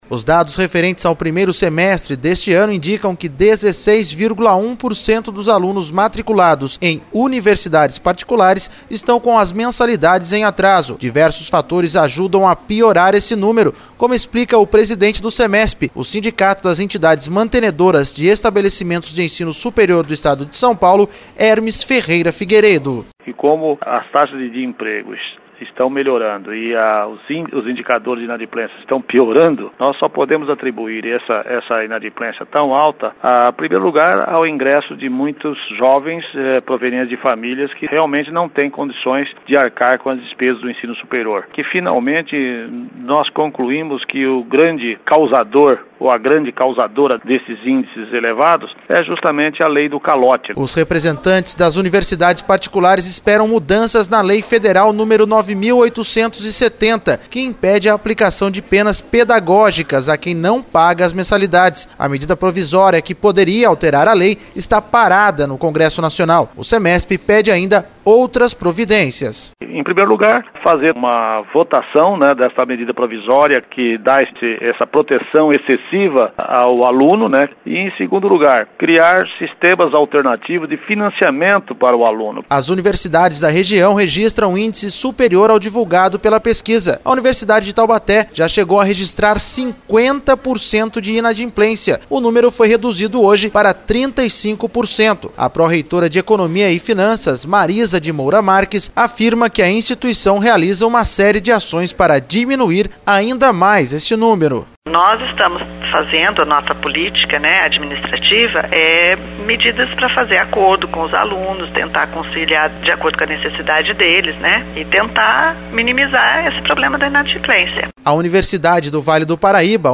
Entrevista.mp3